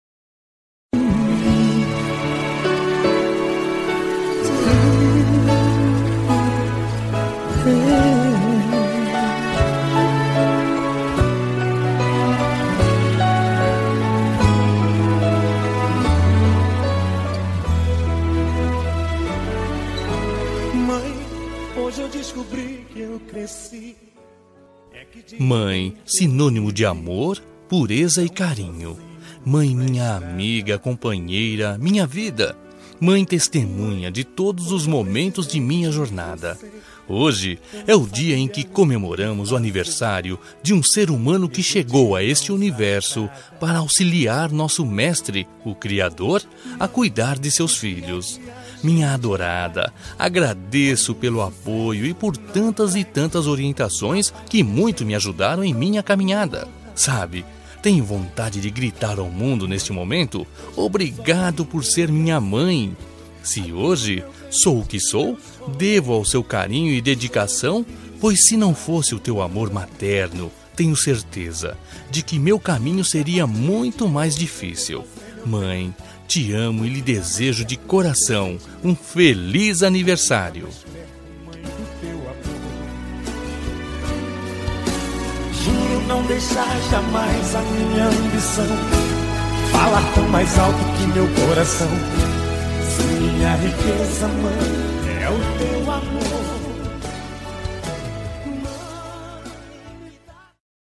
Aniversário de Mãe – Voz Masculina – Cód: 035401